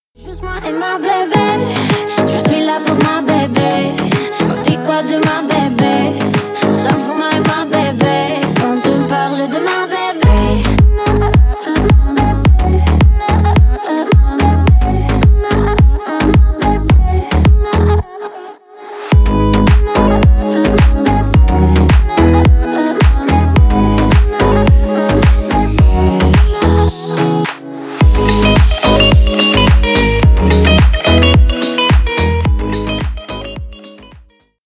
Ремикс # Танцевальные